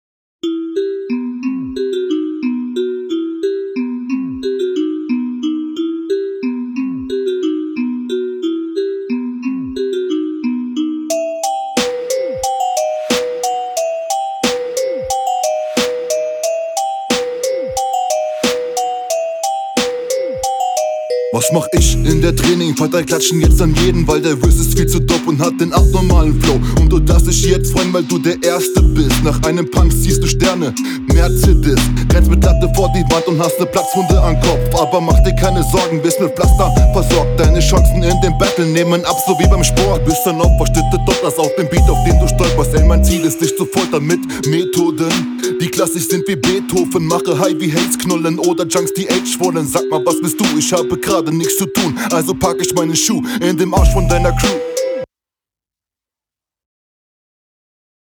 Das mit deiner Abmische ist immer ein Mysterium.
Erstmal, sehr cooler Beat und der Stimmeneinsatz plus Flow passt auch sehr gut.